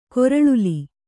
♪ koraḷuli